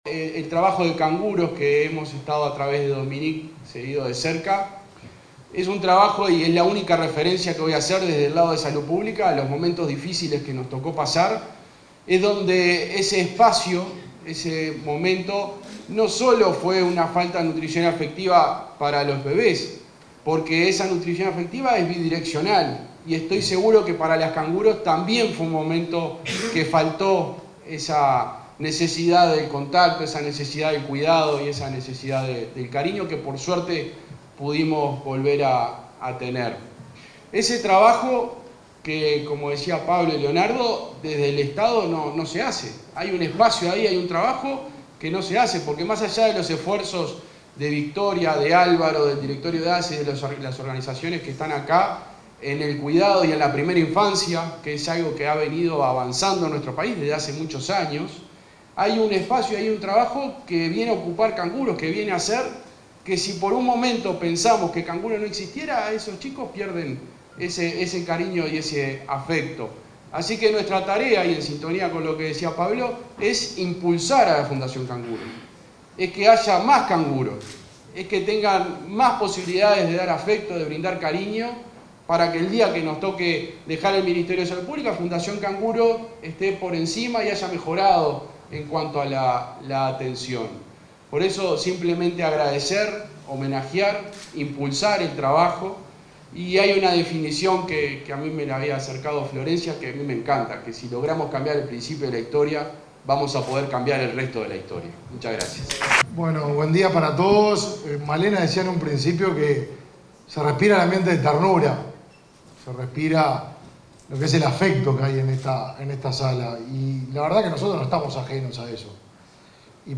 Aniversario de la Fundación Canguro 27/07/2022 Compartir Facebook X Copiar enlace WhatsApp LinkedIn El subsecretario de Salud Pública, José Luis Satdjian; el ministro de Desarrollo Social, Martín Lema, y la vicepresidenta de la República, Beatriz Argimón, participaron en el acto por el sexto aniversario de la Fundación Canguro.